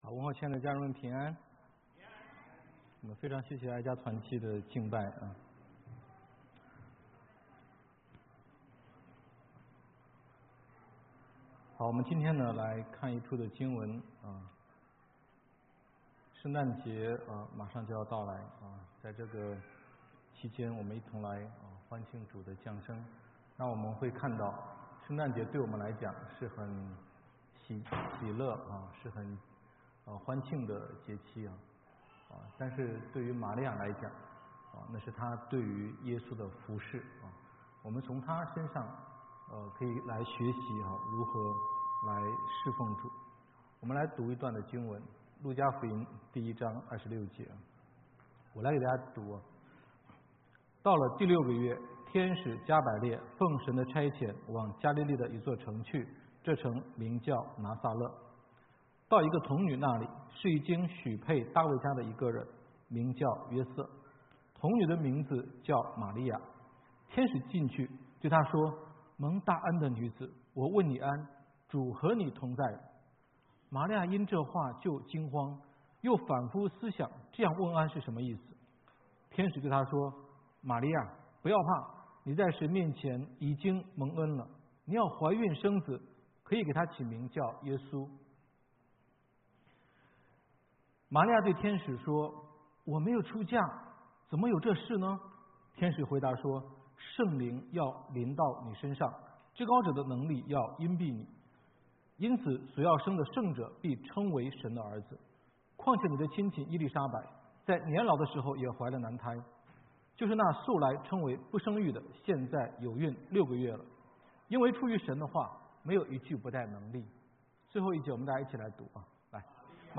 基督之家第五家HOC5 講道